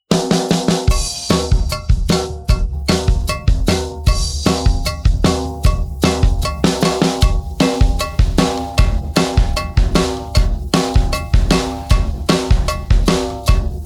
Cow bell